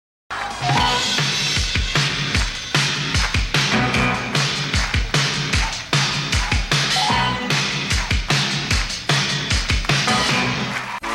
intervalo Meme Sound Effect